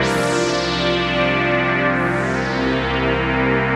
AC_OberPolyA_128-C.wav